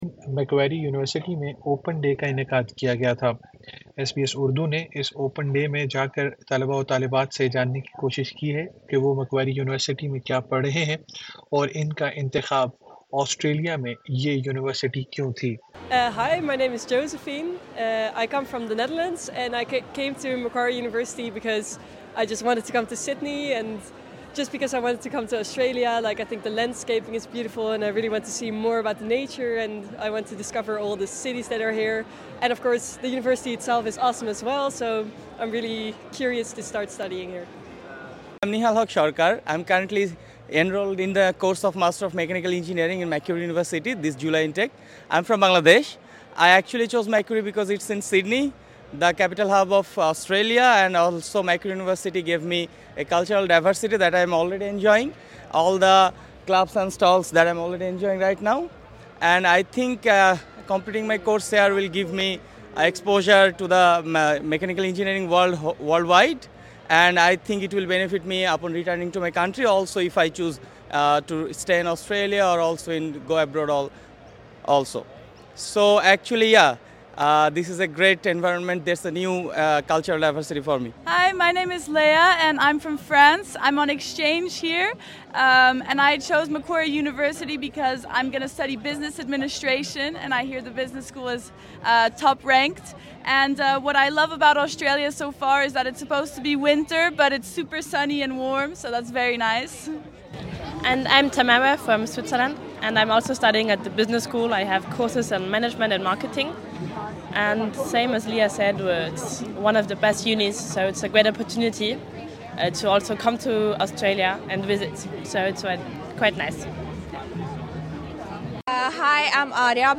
At Macquarie University's Open Day, students from diverse backgrounds shared their thoughts. Students spoke about what drew them to the university and what they hope to achieve in the future.
SBS Urdu 03:16 Urdu In interviews, students cited the university's strong academic reputation, practical course offerings, and supportive learning environment as key reasons for choosing Macquarie.